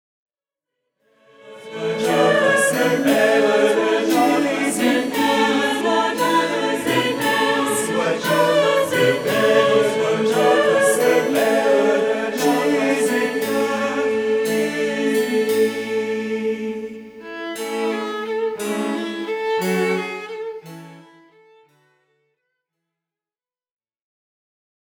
) sont servis par des instruments à cordes et piano.